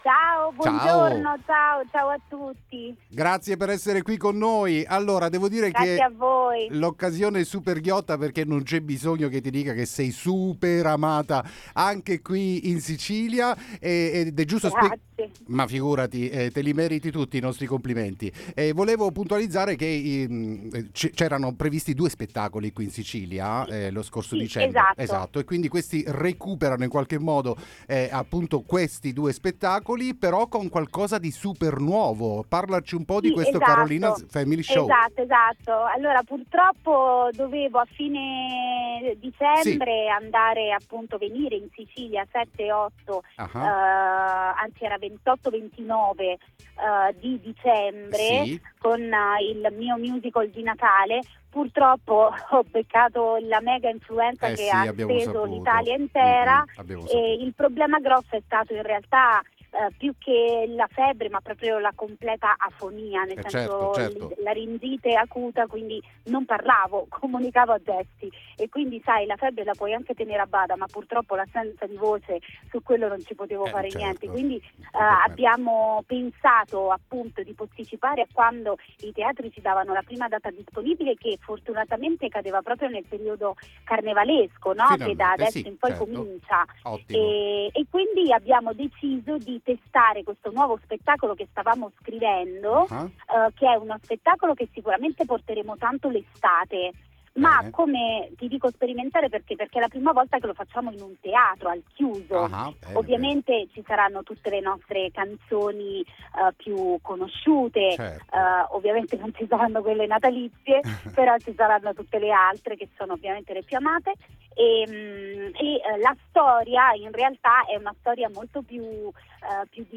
Interviste